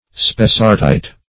Search Result for " spessartite" : The Collaborative International Dictionary of English v.0.48: Spessartite \Spes"sart*ite\, n.[From Spessart, in Germany.]